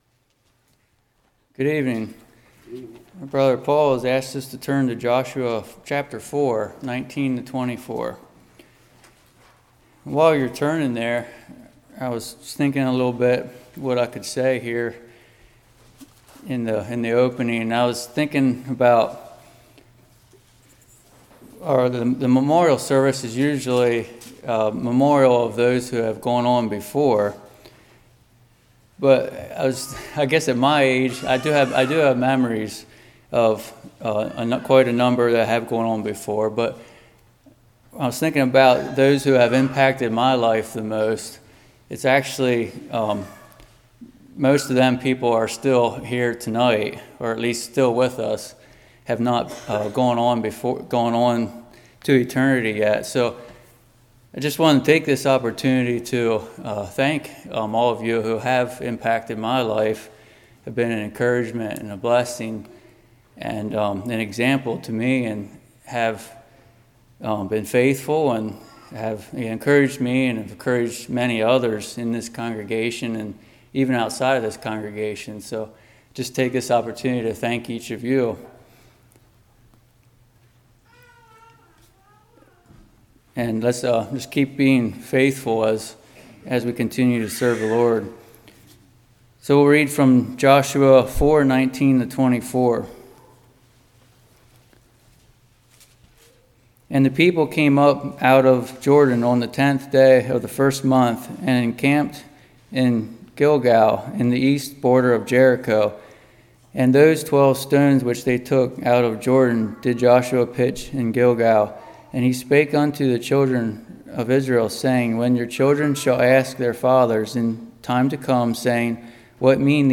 Joshua 4:19-24 Service Type: Evening A faith that is possessed A faith that is proclaimed A faith that is practiced « Whatever It Takes What are you Feasting on?